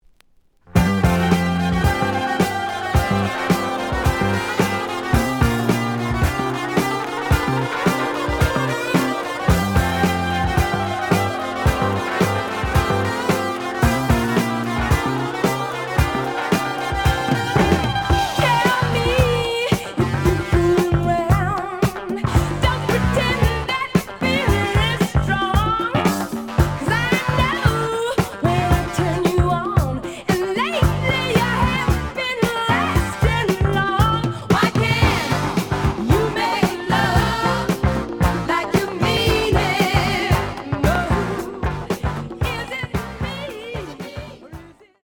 試聴は実際のレコードから録音しています。
●Genre: Disco
●Record Grading: VG~VG+ (傷はあるが、B面のプレイはおおむね良好。)